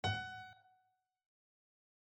FSharp_FADiese.mp3